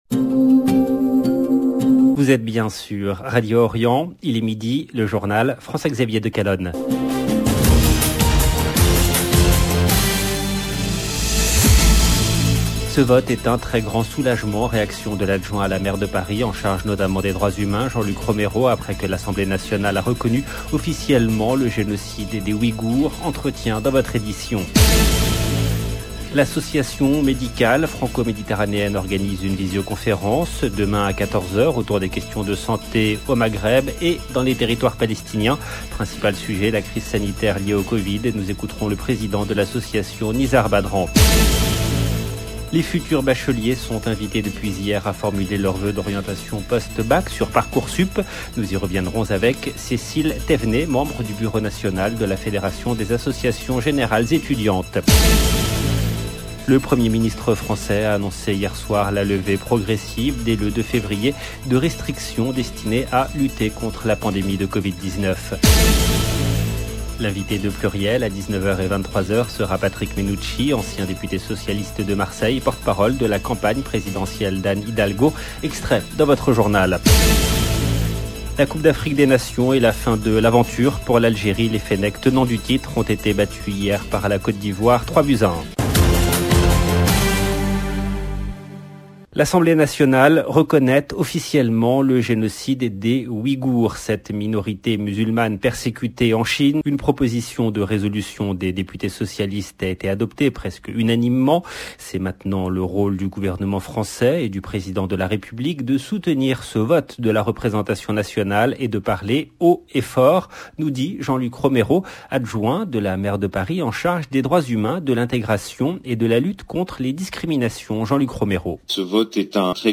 LB JOURNAL EN LANGUE FRANÇAISE
Entretien dans votre édition.